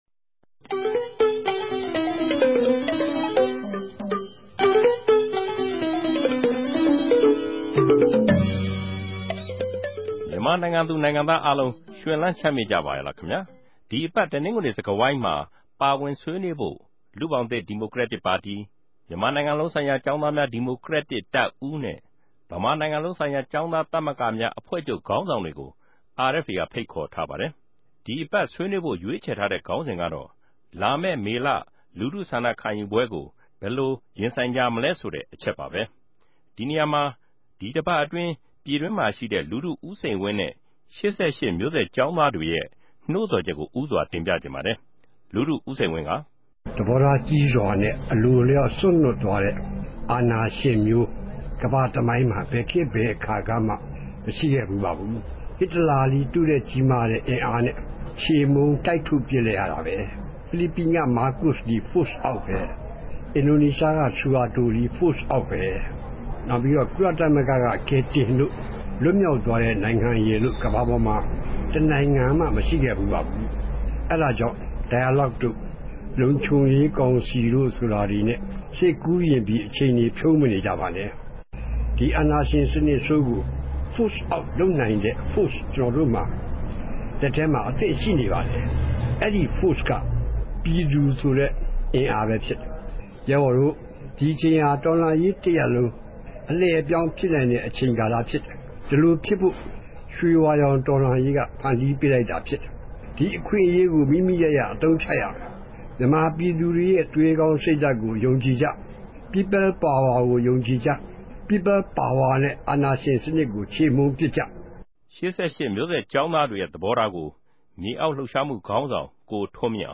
တပတ်အတြင်း သတင်းသုံးသပ်ခဵက် စကားဝိုင်း